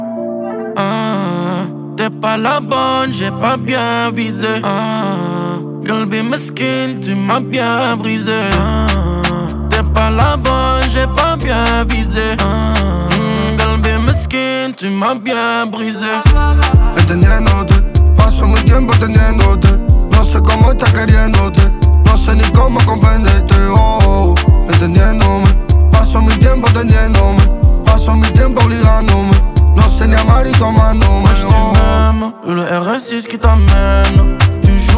Arabic Pop
Жанр: Поп музыка